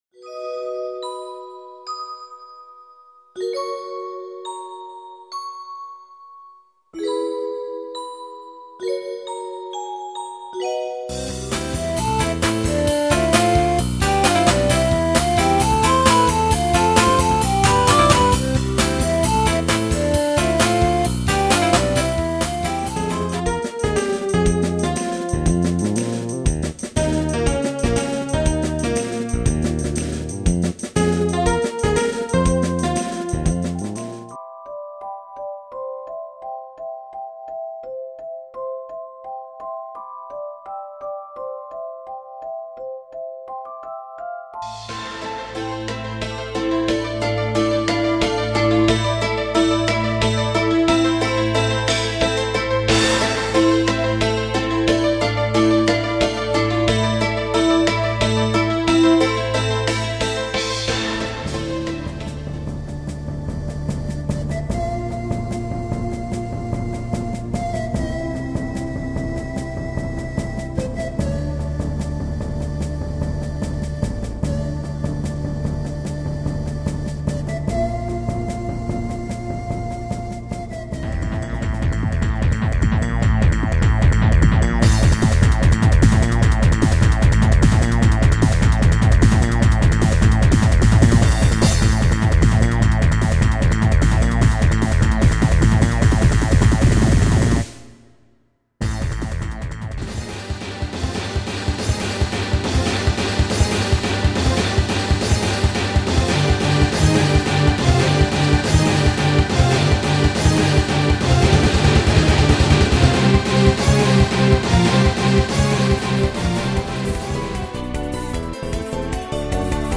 オリジナル音楽ＣＤ付２４Ｐコピー本・即売会価格２００円・発行数：初版５０部